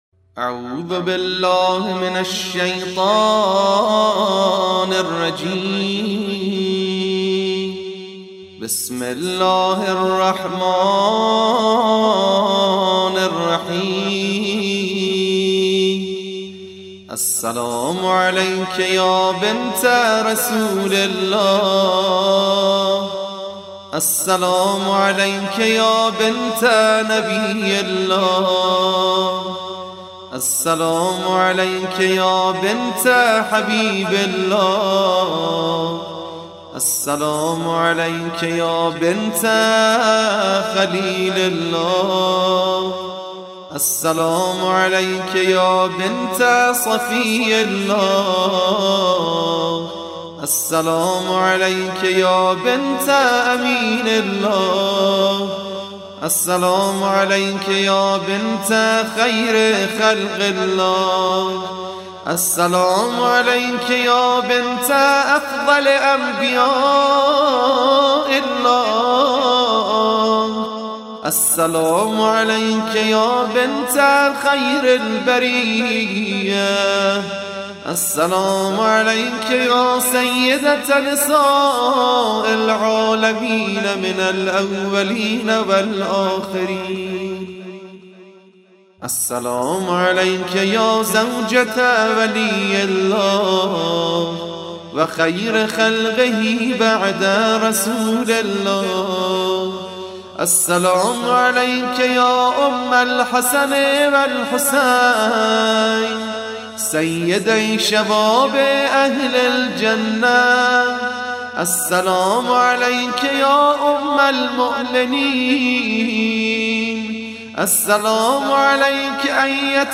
قرائت زیارت حضرت زهرا سلام الله علیها در کتاب اقبال الأعمال (9:13)